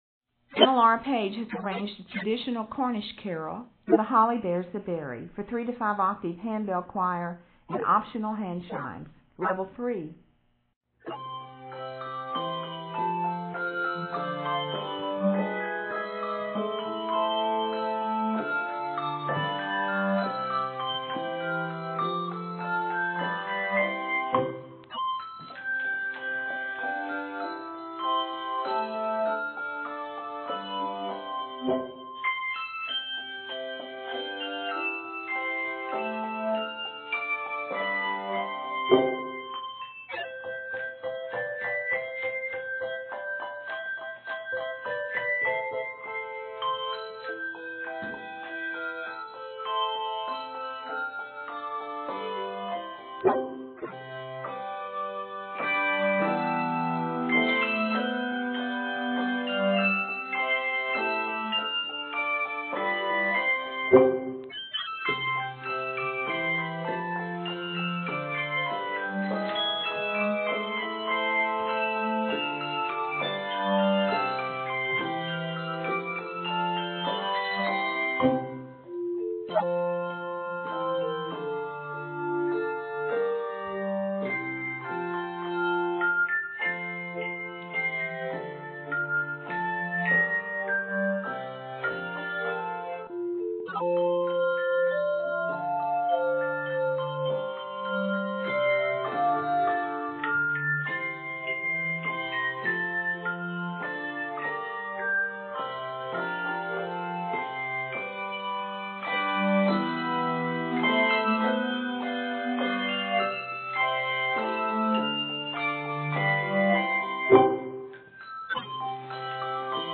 for 3-5 octave handbell choir optional handchimes.